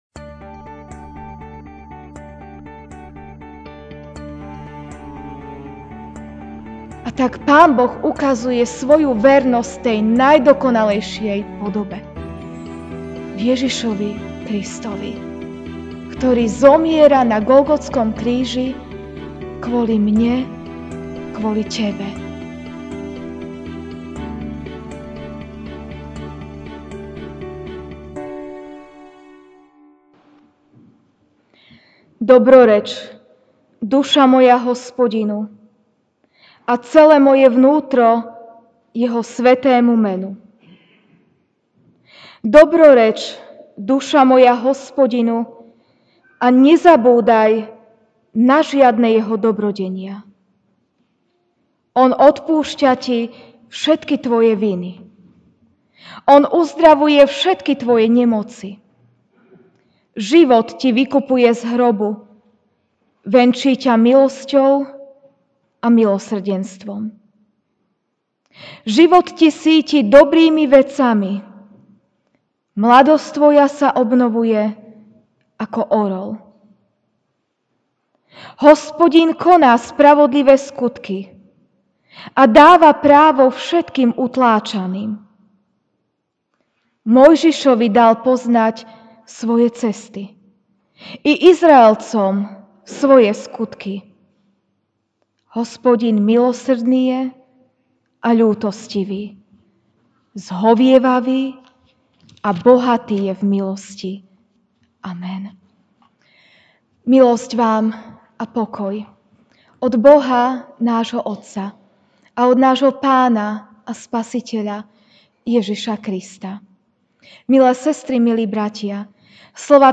dec 31, 2018 Božia vernosť MP3 SUBSCRIBE on iTunes(Podcast) Notes Sermons in this Series Závierka občianskeho roka: Božia vernosť (1K 1, 9) Verný je Boh, ktorý vás povolal do spoločenstva svojho Syna Ježiša Krista, nášho Pána.